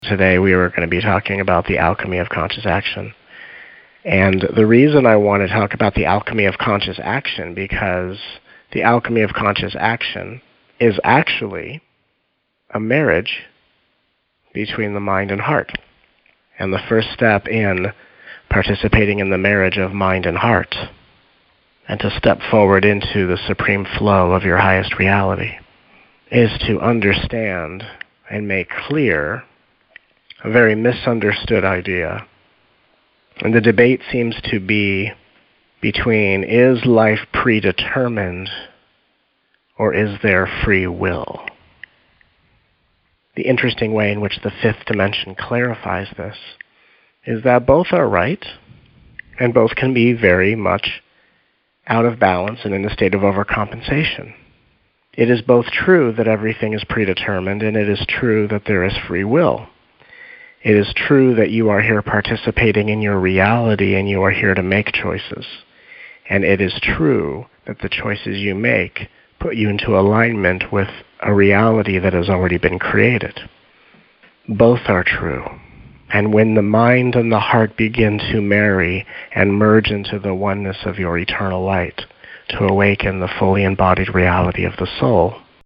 Total running time: 14 hours of teachings, healing energies, activations, and exercises.